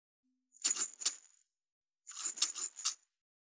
2025年2月19日 / 最終更新日時 : 2025年2月19日 cross 効果音
379,薬瓶振る,シャカシャカ,カラカラ,チャプチャプ,コロコロ,シャラシャラ,